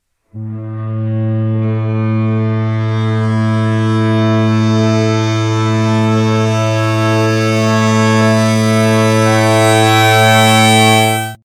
7. Teilton der Obertonreihe  (F, 2F, 3F, 4F, 5F, 6F, 7F) berücksichtigt, ergeben sich noch weitere Intervalle: